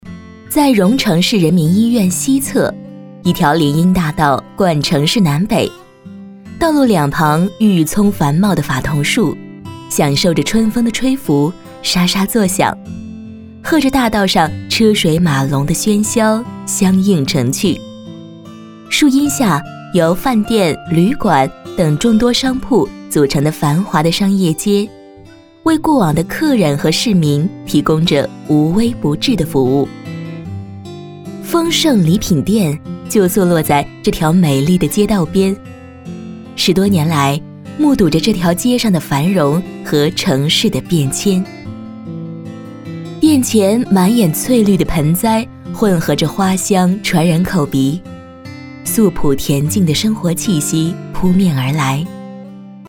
专题女45号（酒店亲切讲述
甜美亲切 企业专题
甜美质感女音。